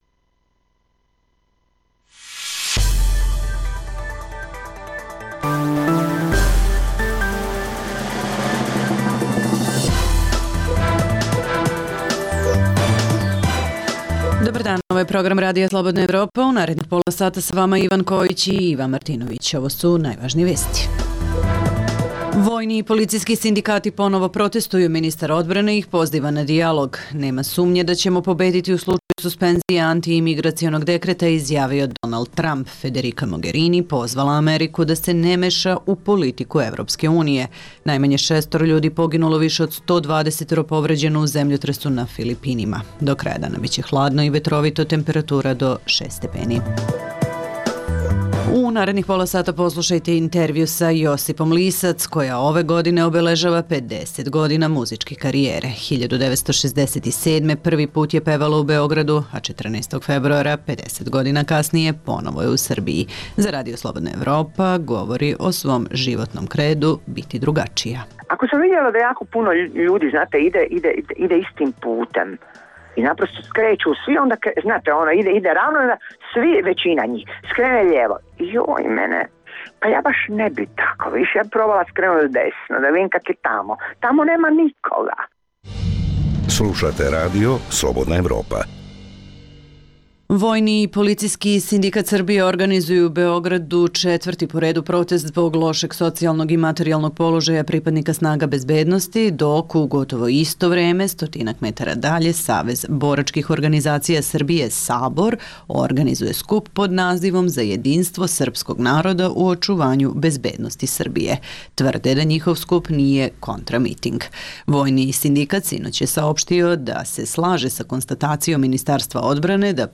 Poslušajte: - vojni i policijski sindikati u Srbiji ponovo protestuju, ministar odbrane ih poziva na dijalog; - kako je američki predsednik komentarisao suspenziju antiimigracionog dekreta, ali i šta je SAD-u poručila evropska šefica diplomatije; - intervju sa Josipom Lisac koja ove godine obeležava 50 godina muzičke karijere i 14. februara nastupa u Beogradu.